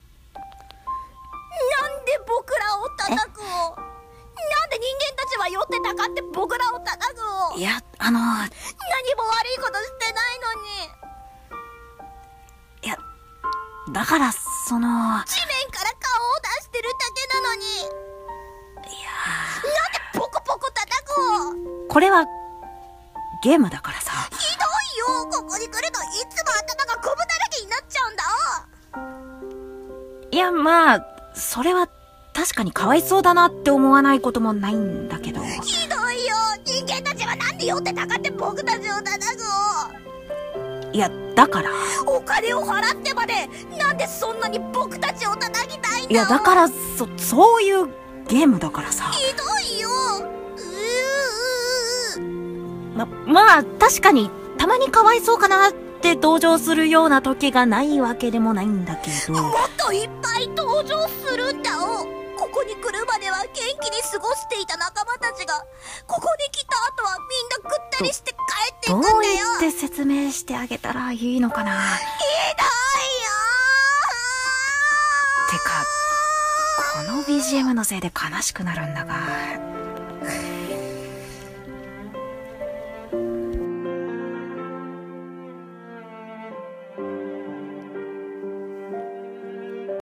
ギャグ？台本【モグラ叩きの憂鬱】 2人声劇